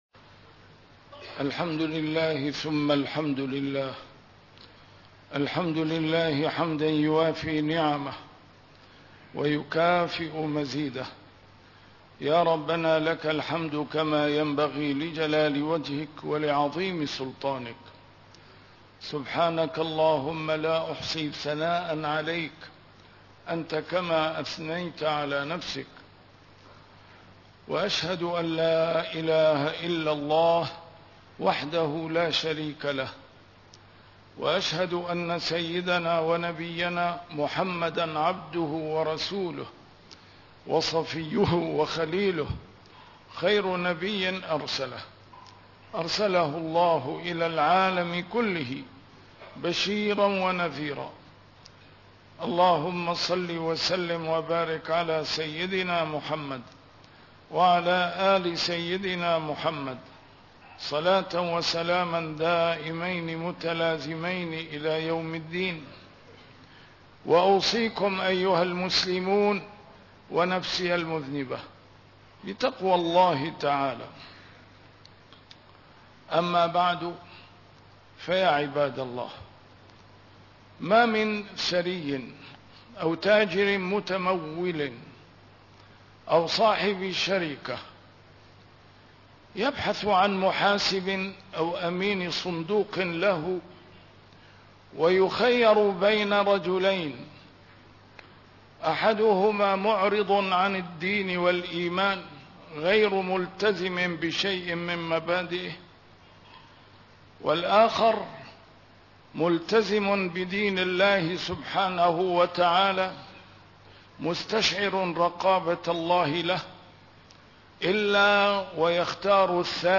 A MARTYR SCHOLAR: IMAM MUHAMMAD SAEED RAMADAN AL-BOUTI - الخطب - الدين الحق سبيلنا الوحيد لإصلاح المجتمع الإنساني